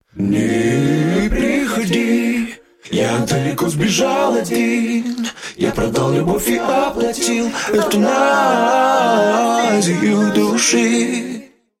Жанр: Хип-хоп / Русский рэп